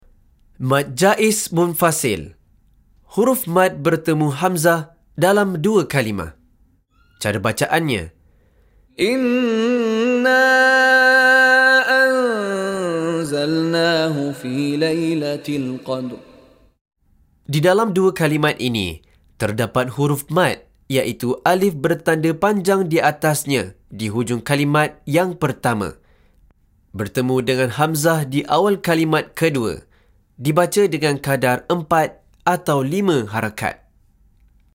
Penerangan Hukum + Contoh Bacaan dari Sheikh Mishary Rashid Al-Afasy
DIPANJANGKAN sebutan huruf Mad dengan kadar 4 atau 5 harakat